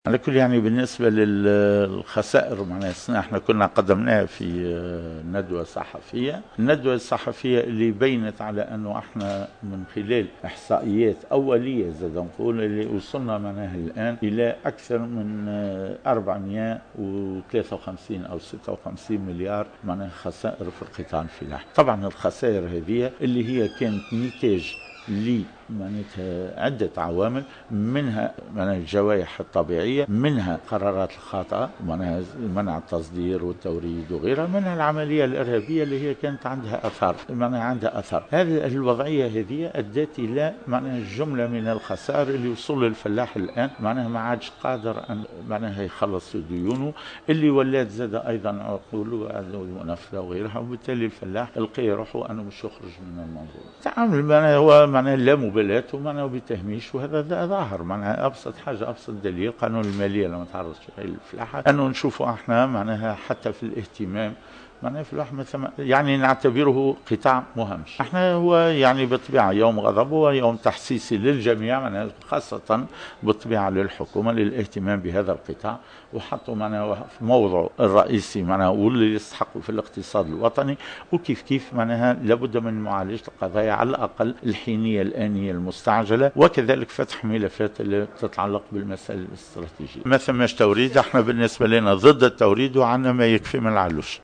خلال وقفة احتجاجية نظمها الاتحاد اليوم الأربعاء 02 سبتمبر 2015 تحت شعار "يوم غضب الفلاح"